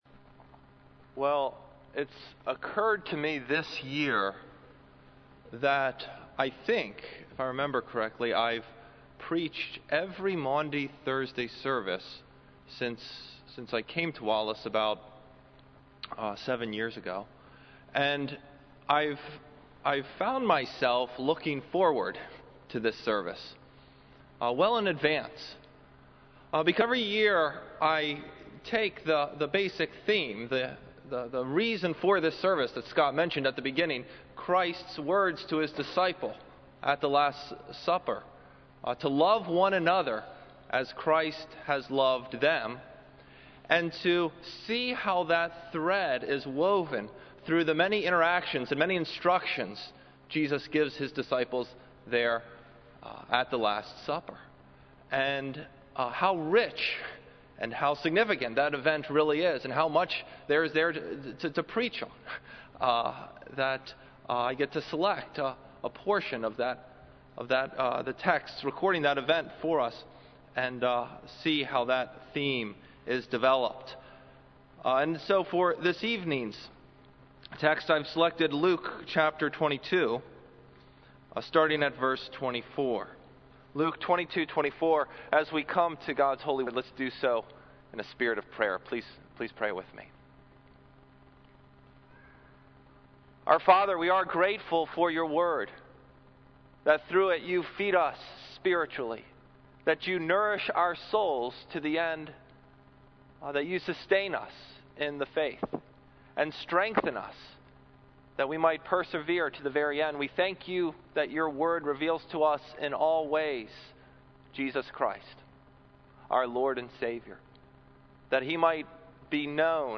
Wallace Presbyterian Church
Maundy Thursday Homily